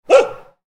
Woofing Dog Sound Effect
The dog barks once. A single “woof” sound from a dog.
Woofing-dog-sound-effect.mp3